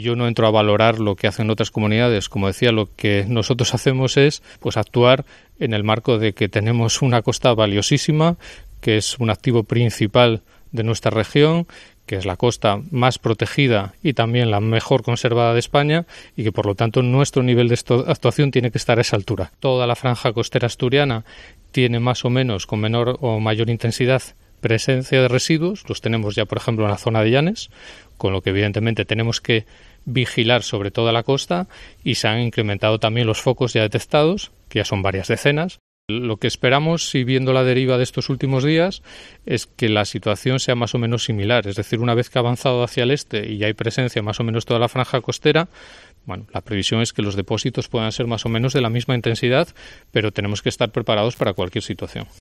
Alejandro Calvo justifica la elevación de la alerta por microplásticos